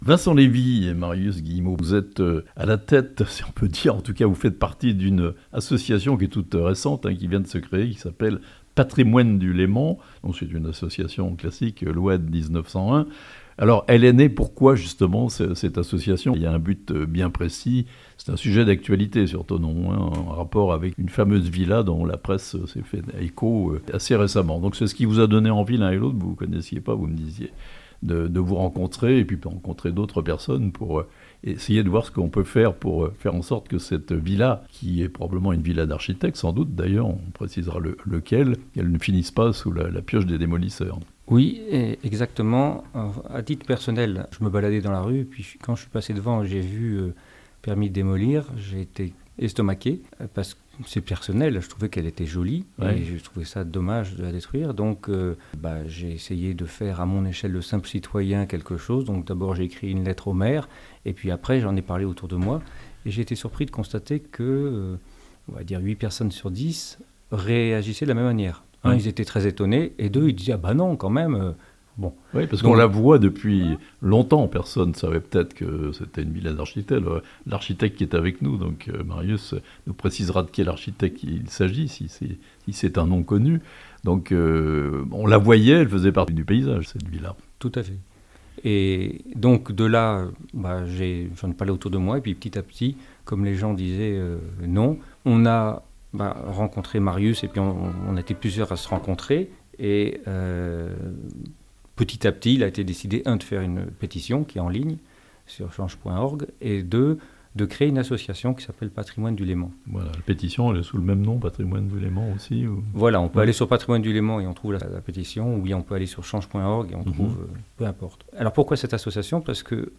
Une association s'oppose à la destruction d'une villa atypique à Thonon (interviews)